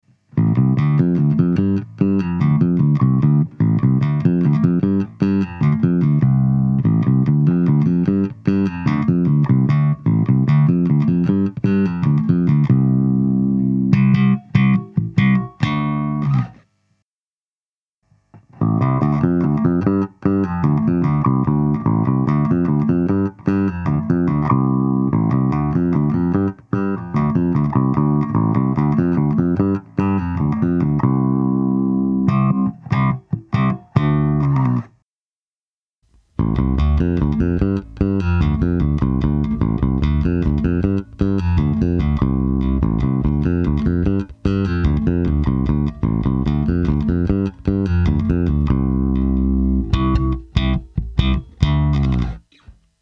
������� ��������� �����: �������� Ampeg BA-110 1,77 �� Rode M2, SM57, �������� �����
ampeg_ba-110.mp3